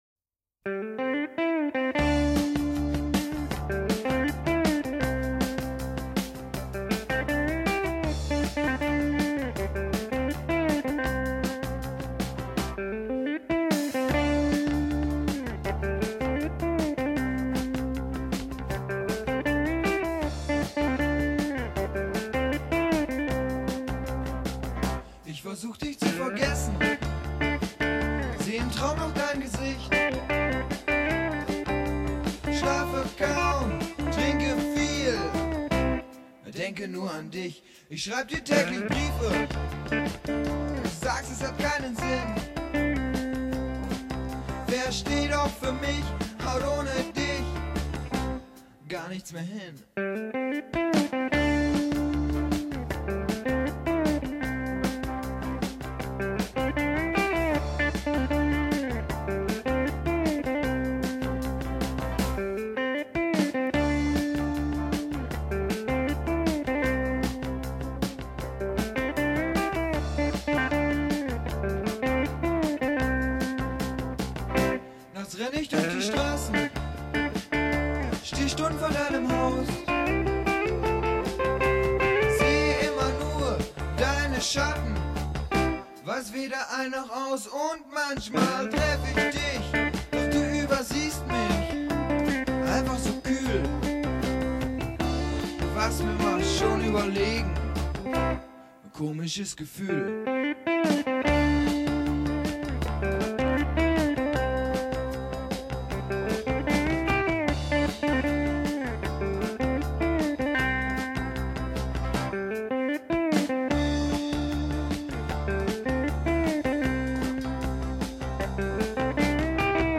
Neue Deutsche Welle aus den 80ern